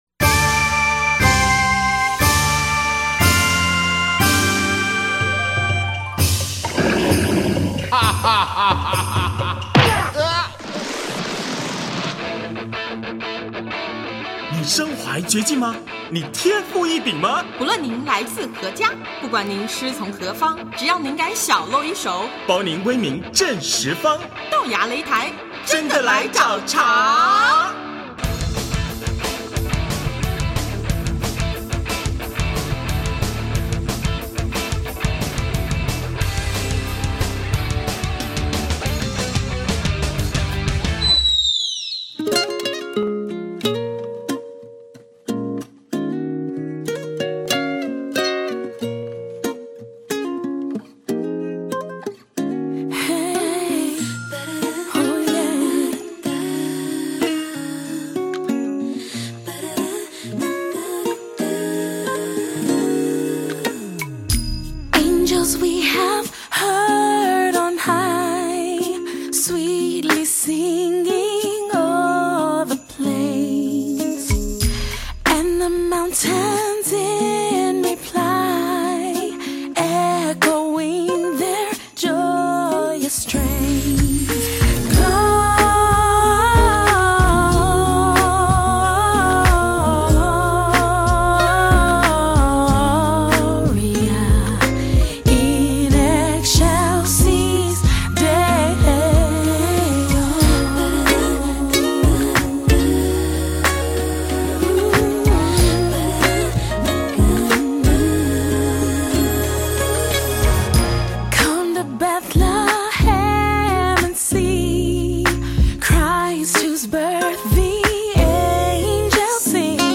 高亢、圆润，完全不慵懒，很有精神，用丹田发声